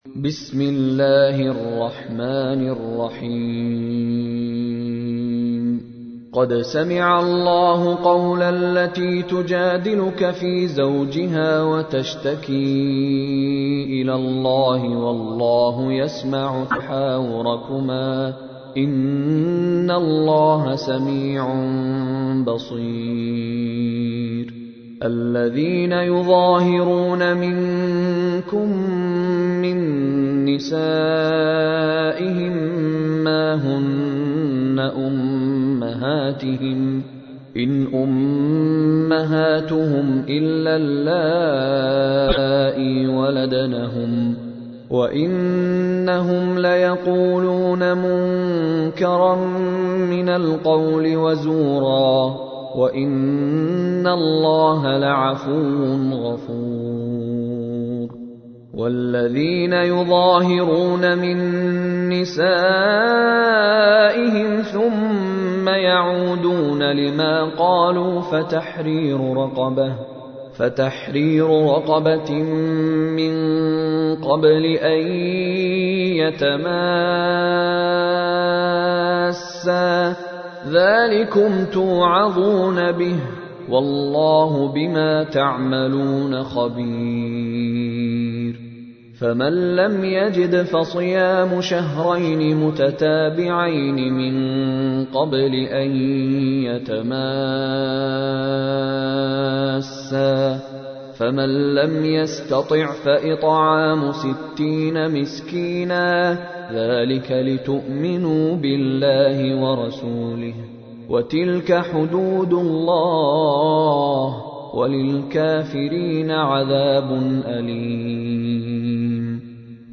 تحميل : 58. سورة المجادلة / القارئ مشاري راشد العفاسي / القرآن الكريم / موقع يا حسين